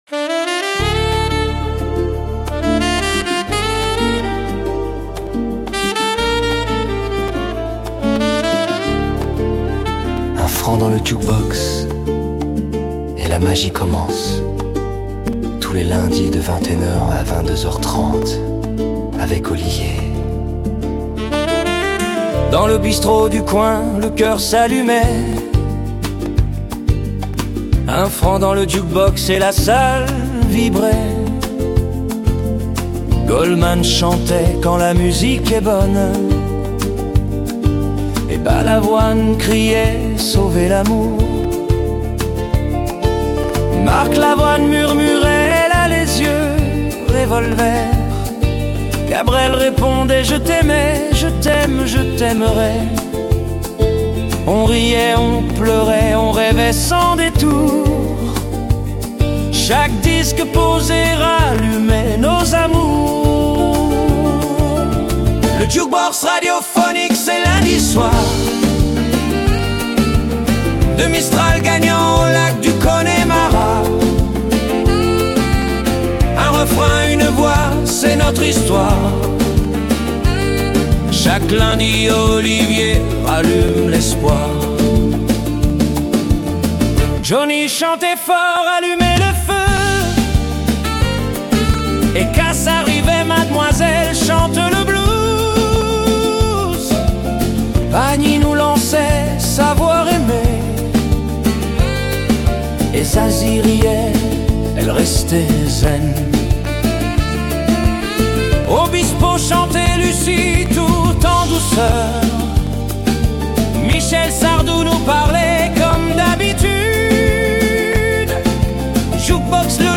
Le Jukebox Radio Phonic – Podcast du lundi 20 avril Chaque lundi soir, le Jukebox Radio Phonic ouvre ses portes… et vous embarque pour un voyage musical sans frontières.